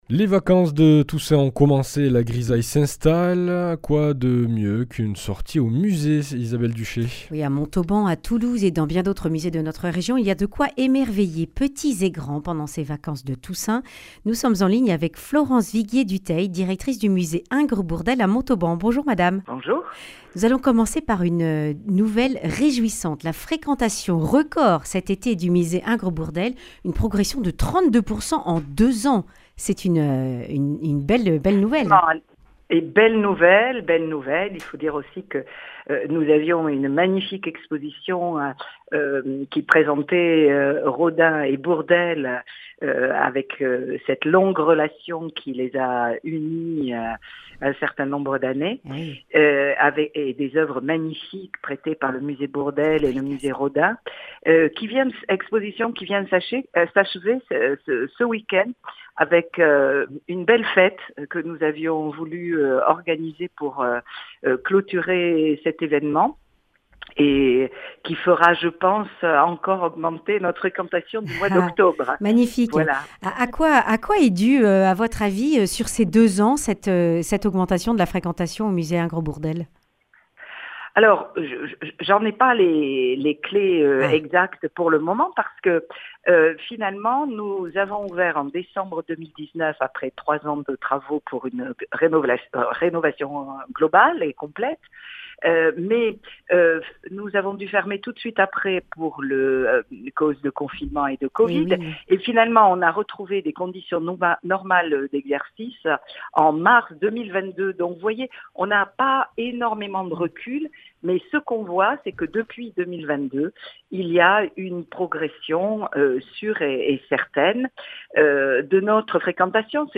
mercredi 22 octobre 2025 Le grand entretien Durée 10 min
Une émission présentée par